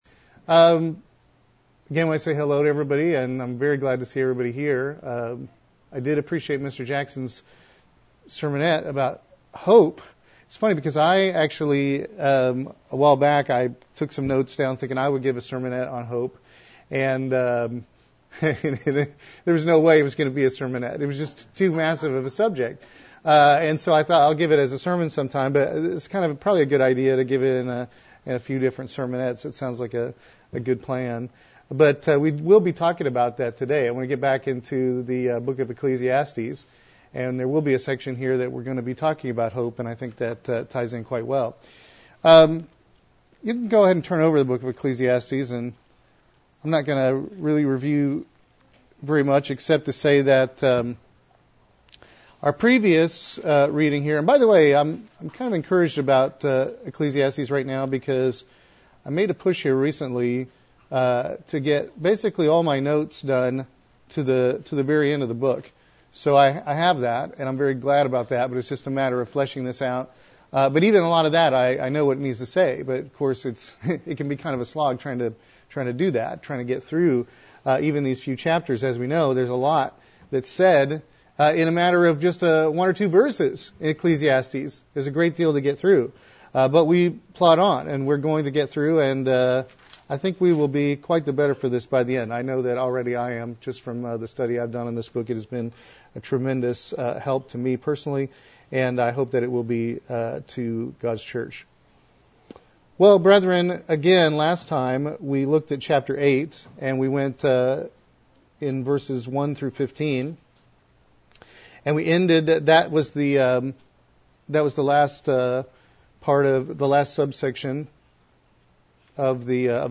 Sermons
Given in Columbia - Fulton, MO